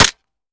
weap_golf21_fire_last_plr_mech_03.ogg